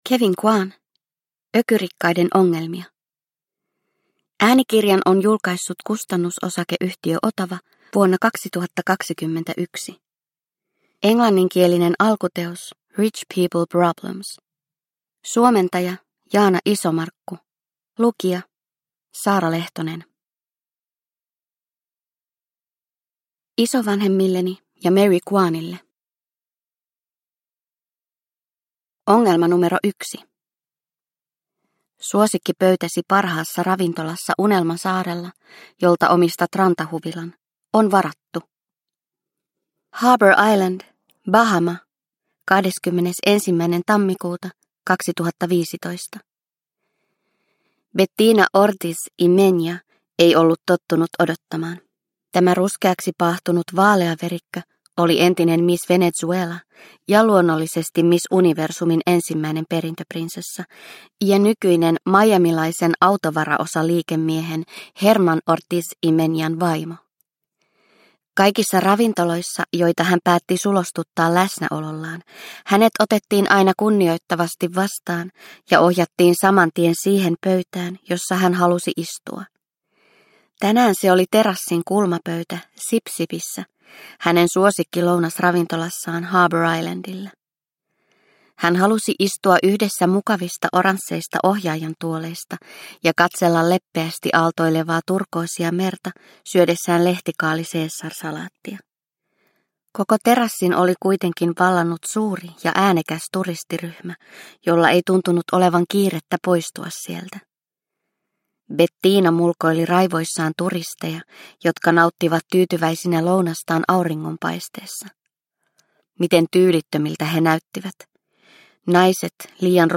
Ökyrikkaiden ongelmia – Ljudbok – Laddas ner